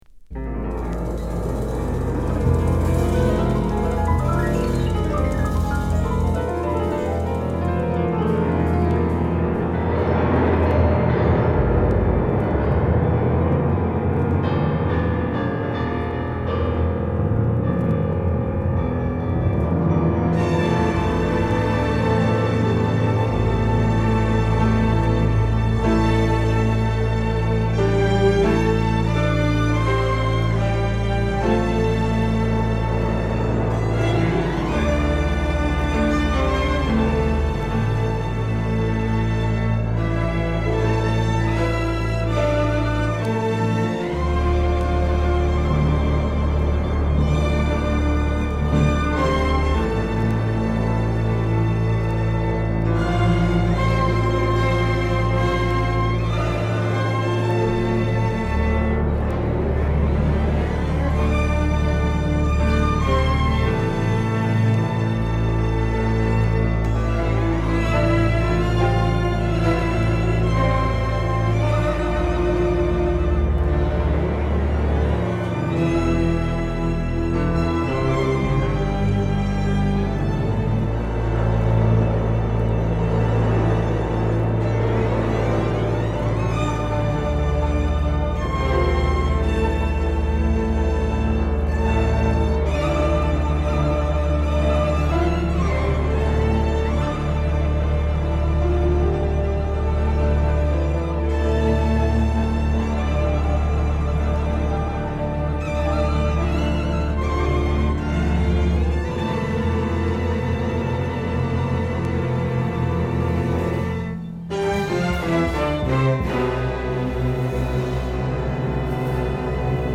ほぼドラム無しのアヴァンギャルド・ジャズを披露！